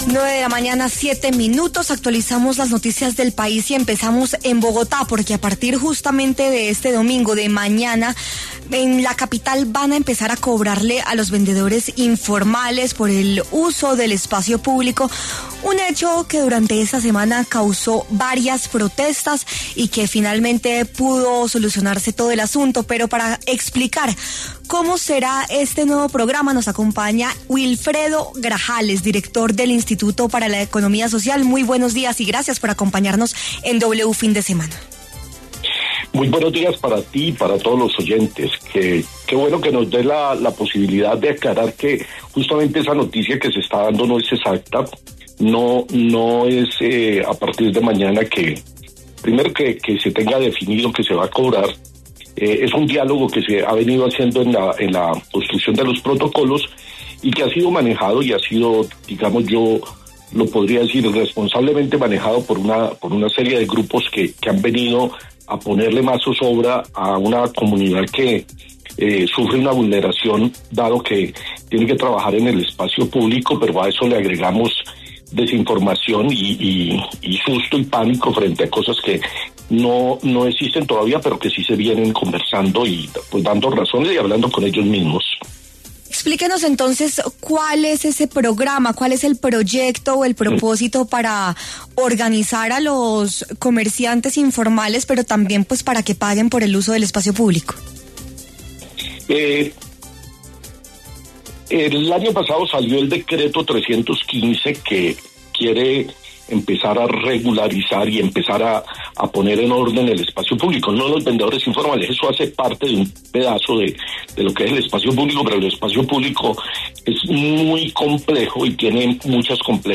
Sin embargo, la noticia desató polémica y, por este motivo, en los micrófonos de W Fin De Semana habló el director del IPES, Wilfredo Grajales, quien aclaró la coyuntura.